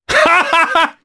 Baudouin-Vox-Laugh_jp.wav